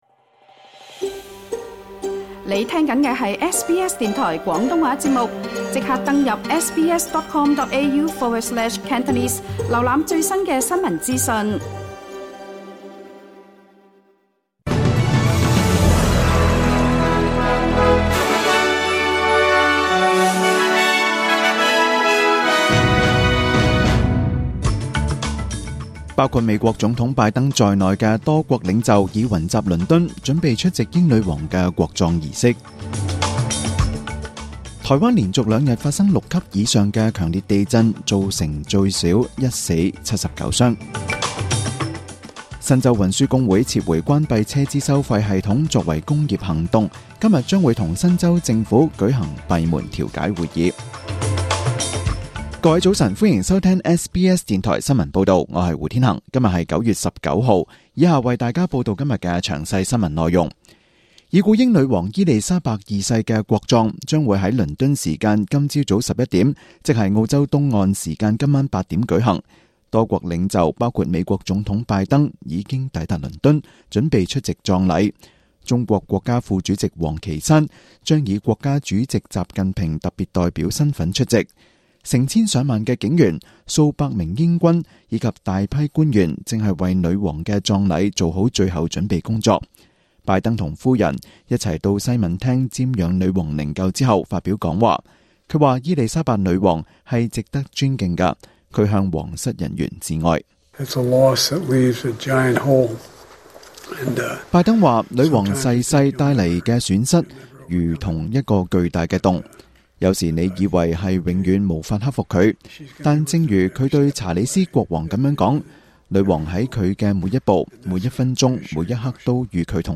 SBS廣東話節目中文新聞 Source: SBS / SBS News